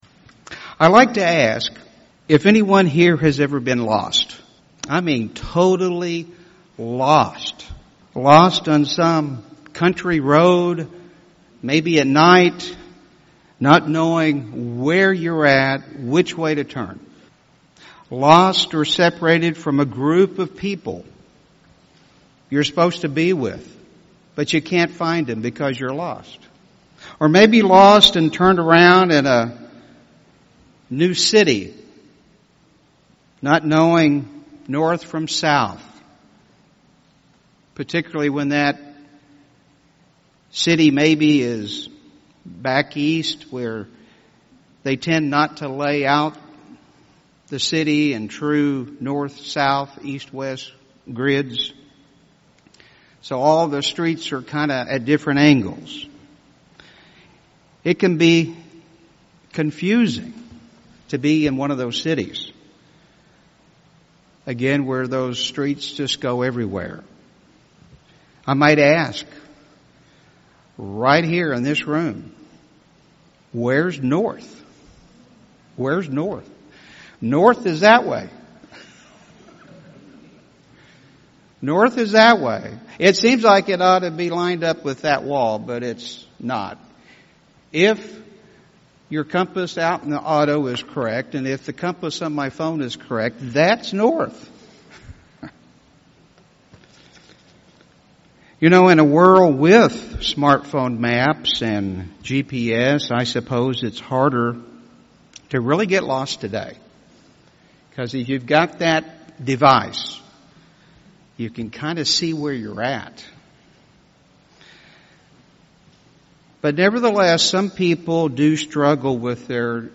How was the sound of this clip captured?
Given in Tulsa, OK